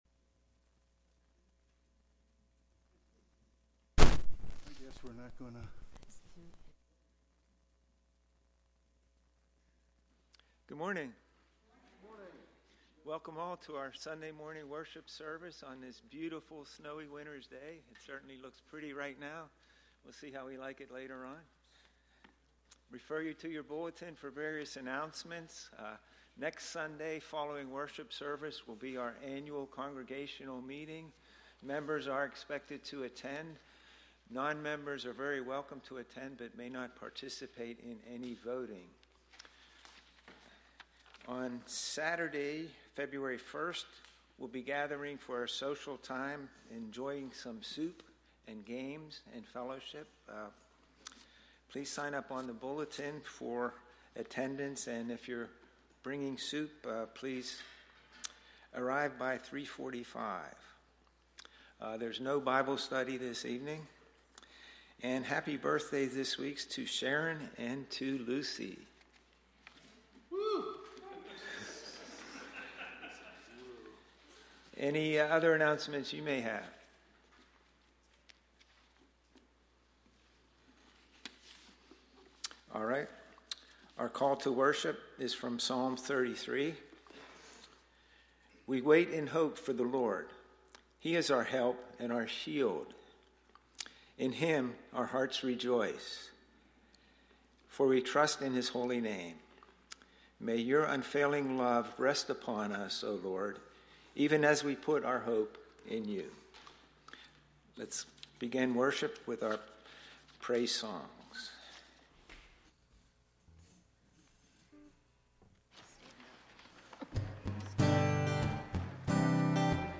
1 Sunday Worship January 19, 2025 50:22